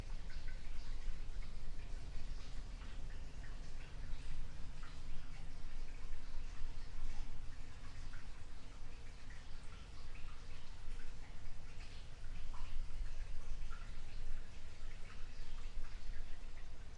威尼斯声音/接触麦克风/水听器/ " 排水管接触麦克风威尼斯
描述：排水管接触麦克风威尼斯
Tag: 泄水 威尼斯 接触话筒